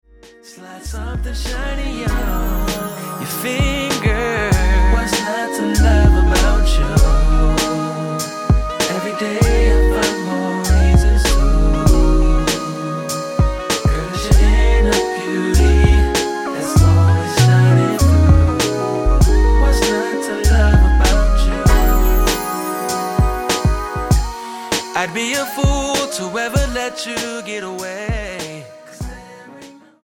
HIPHOP, RAP KARAOKE CDs
NOTE: Vocal Tracks 10 Thru 18